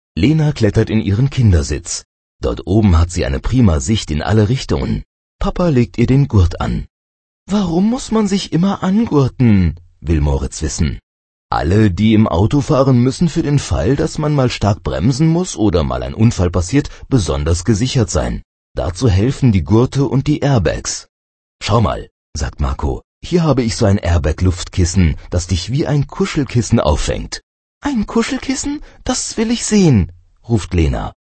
Hörbuch Seite 9